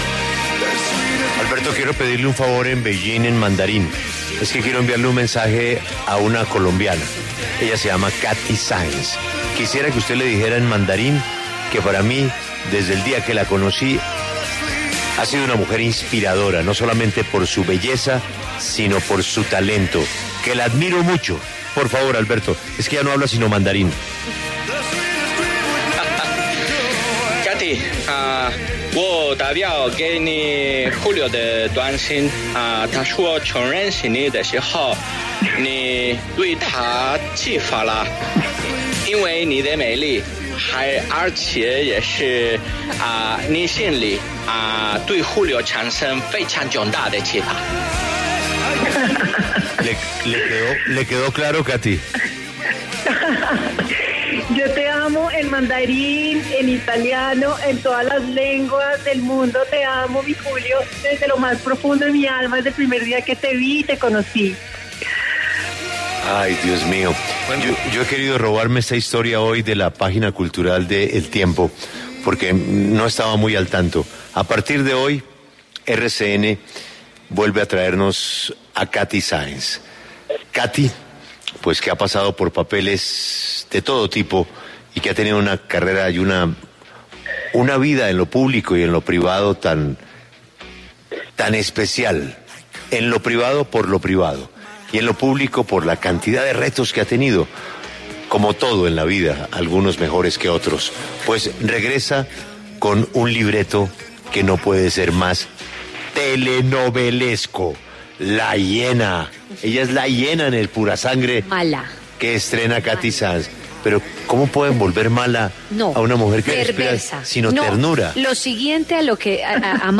En La W, la actriz Kathy Sáenz habla sobre la repetición de la exitosa novela ‘Pura Sangre’ en el canal RCN y sobre su trabajo actual como emprendedora.